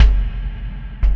Kick Particle 12.wav